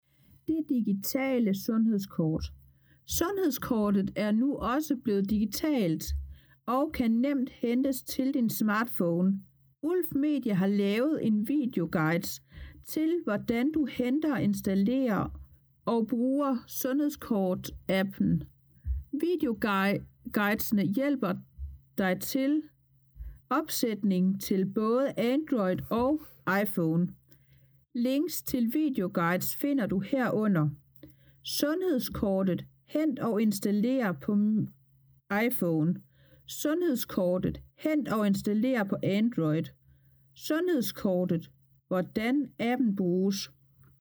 Siden er speaket hér: